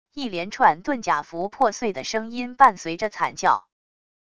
一连串遁甲符破碎的声音伴随着惨叫wav音频